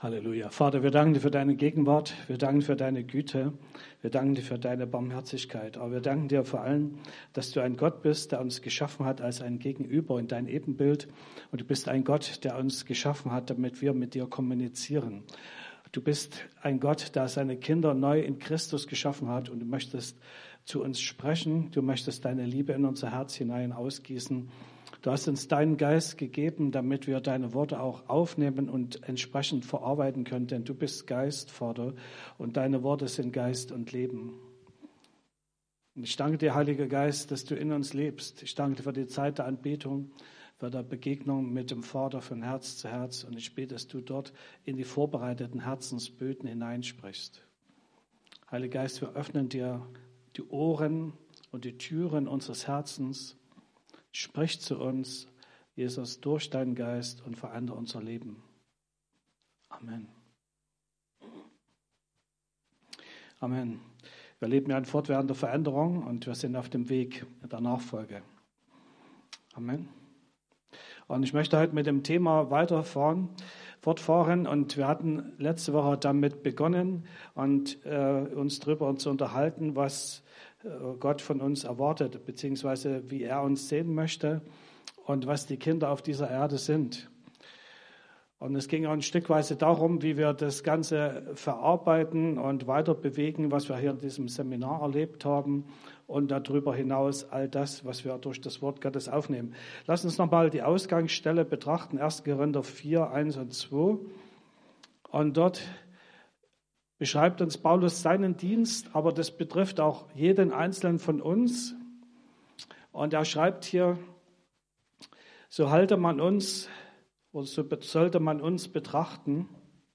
Predigten chronologisch sortiert